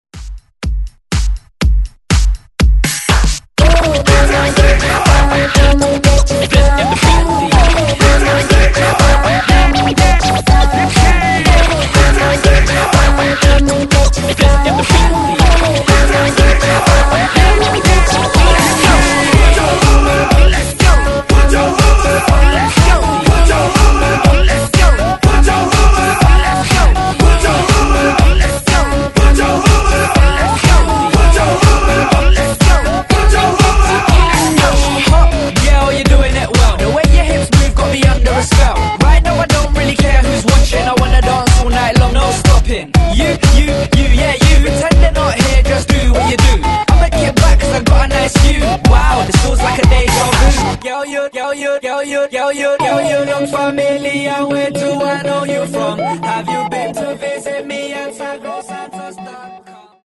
Genres: 2000's , TOP40 Version: Clean BPM: 123 Time